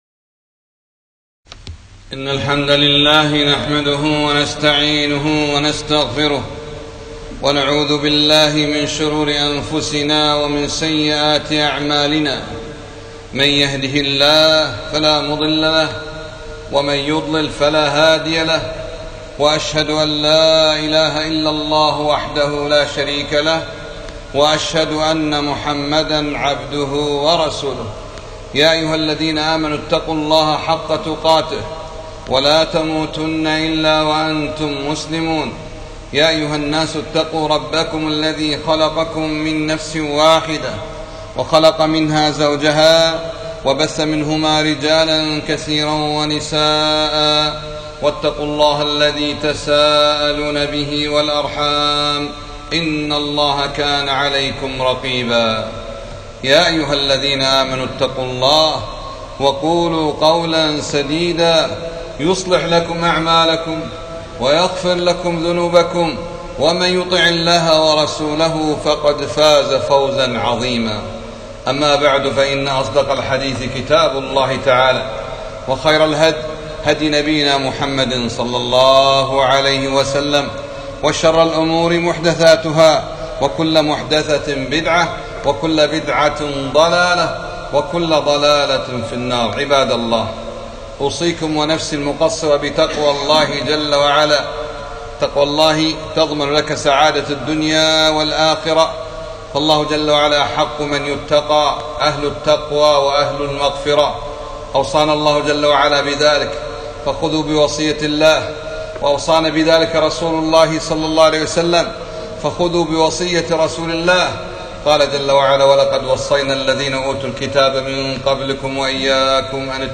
خطبة - ثق بالله ياعبد الله ولا تخشى الفقر